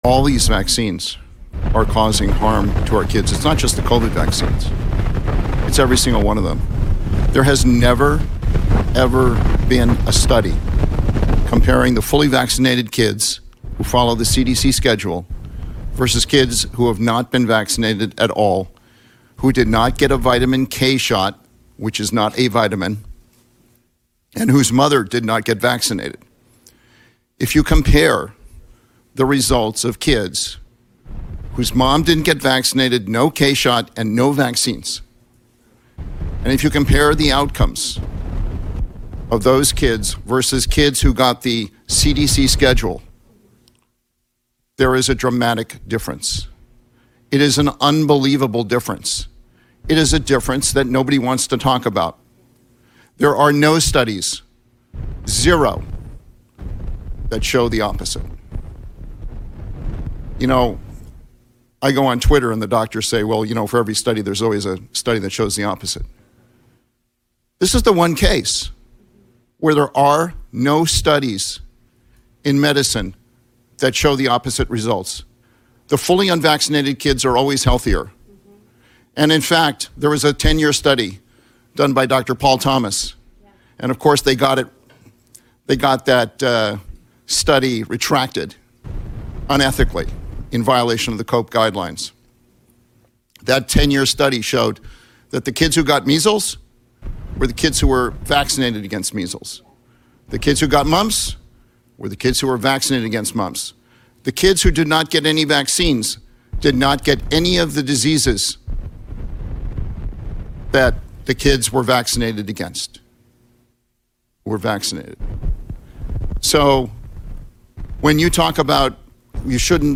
Testifies The Truth About All Vaccines To Pennsylvania State Senate